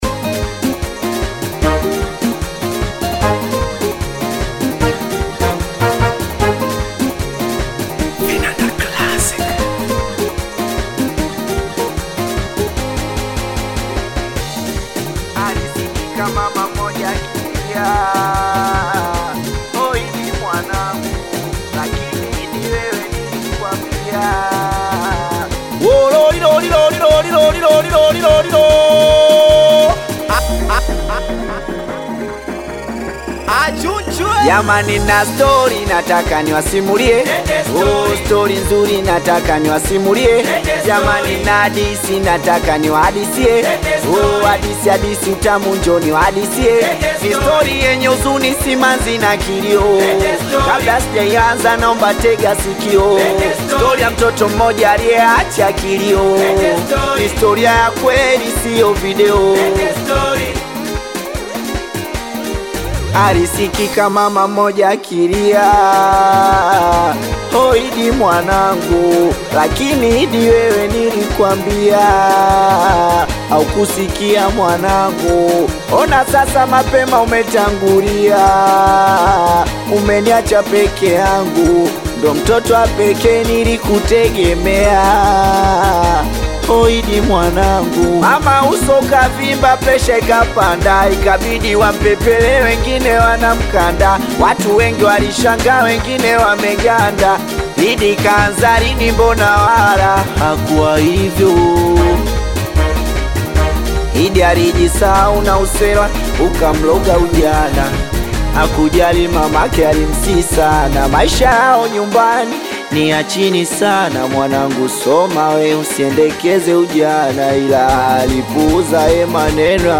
Singeli music track
Tanzanian Bongo Flava singeli artist, singer and songwriter
Singeli song